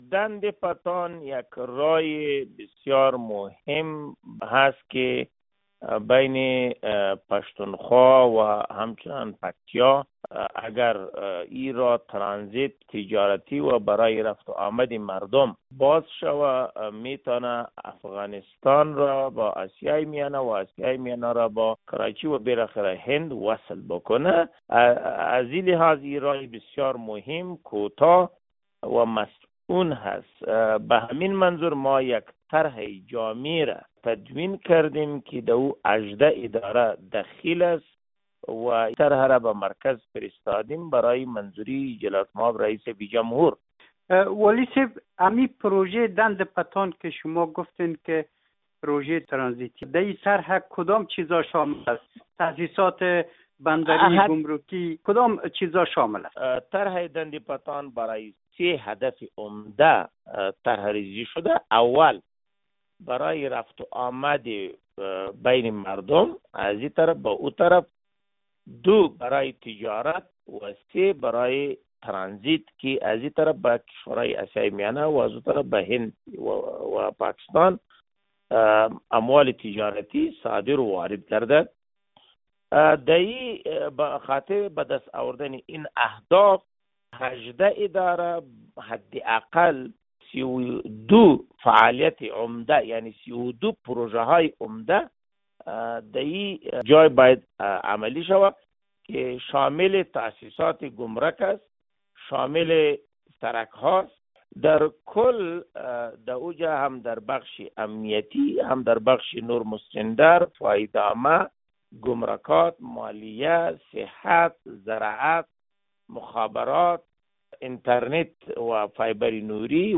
گفتگو با والی پکتیا در پیوند به طرح ترانزیت دند پتان